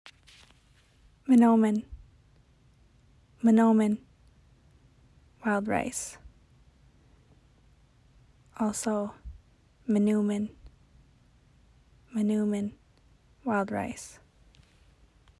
Anishinaabemowin pronunciation: "mun-oo-min"